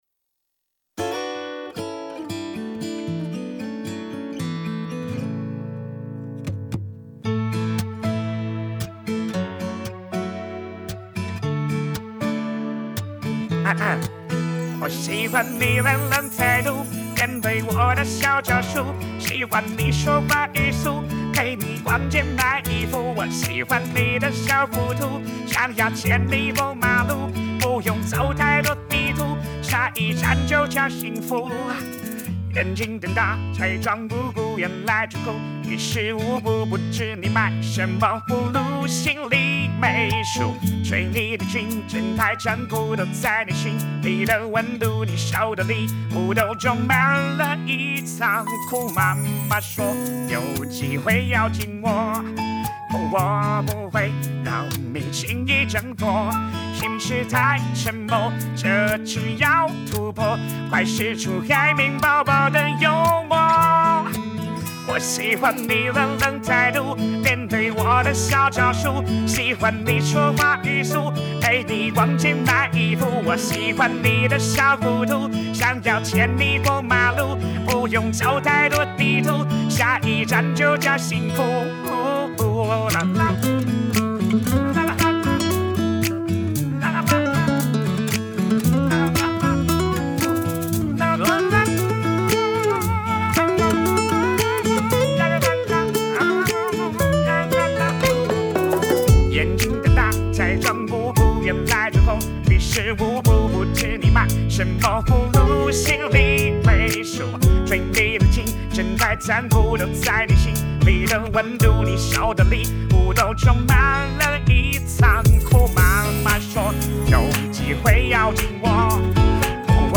配音尖锐鼻音，富抑扬顿挫，体现高傲、烦躁与无奈，语调起伏强烈，极具辨识度。